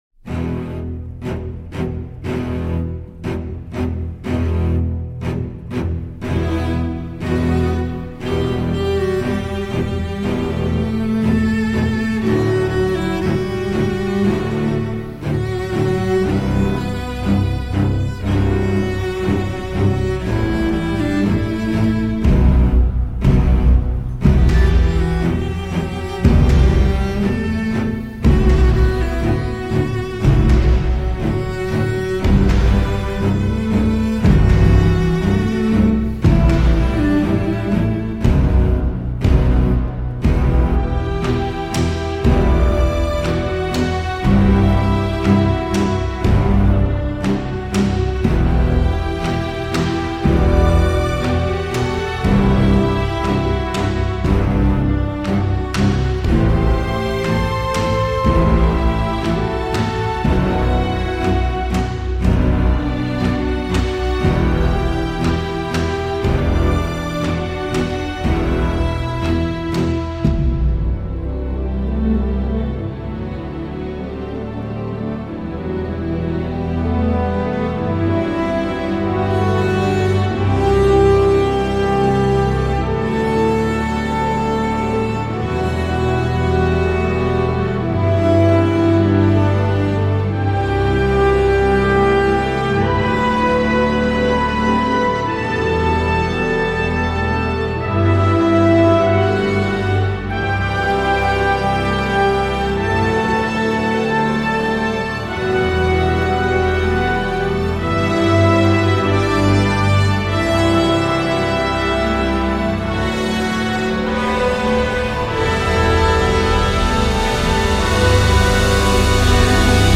Et l’univers sonore est intéressant.